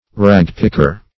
Ragpicker \Rag"pick`er\ (r[a^]g"p[i^]k`[~e]r), n.